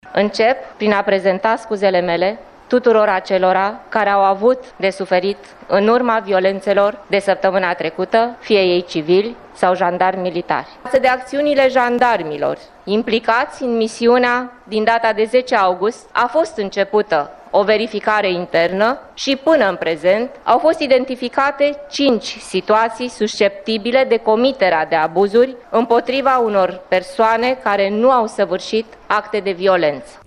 Într-o declaraţie de presă, demnitarul a prezentat duminică, 19 august, detalii din raportul legat de intervenţiile de acum două săptămâni, realizat de structurile MAI.